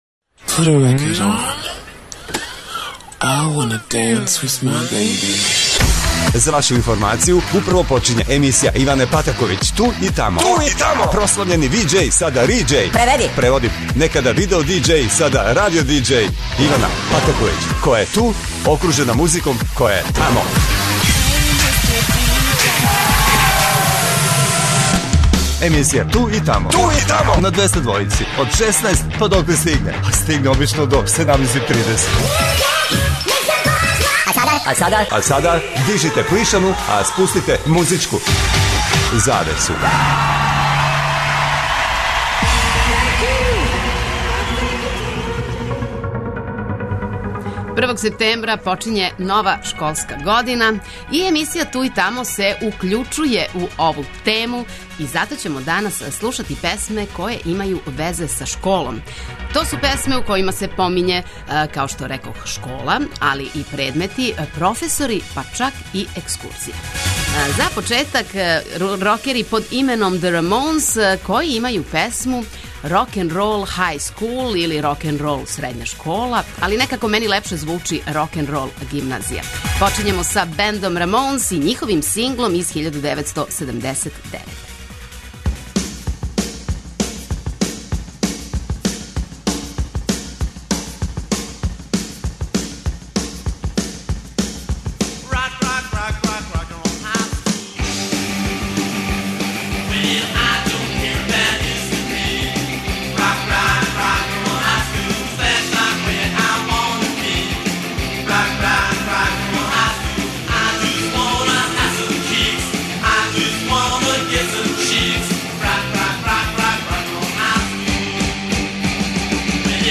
Слушаћемо стране и домаће песме у којима се помињу ученици, професори, часови, предмети и остале школске ствари...